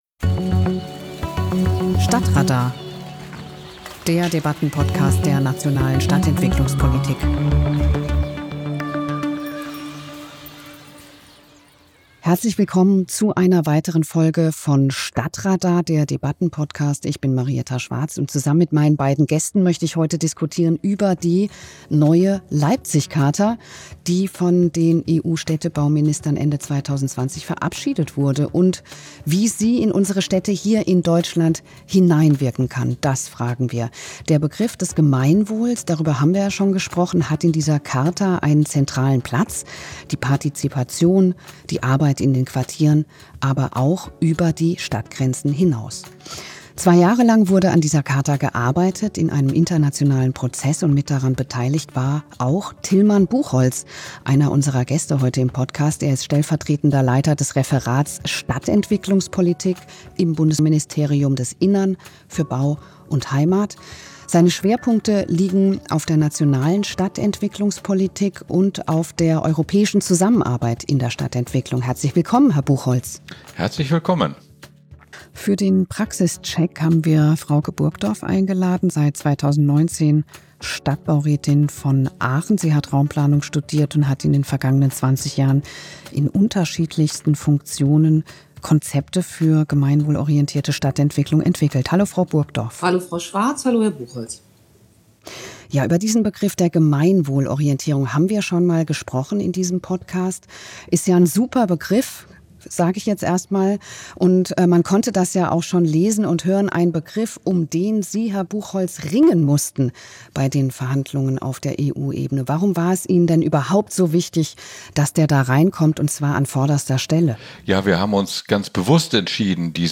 stadt:radar - Der Debattenpodcast der Nationalen Stadtentwicklungspolitik